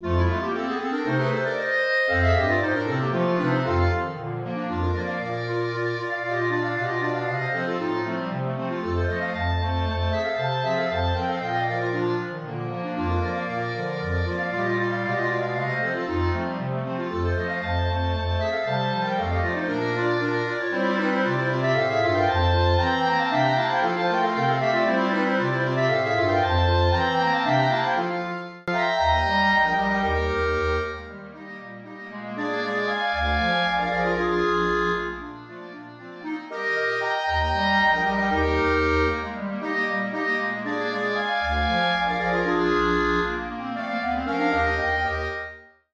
für Klarinettenquartett/Saxophonquartett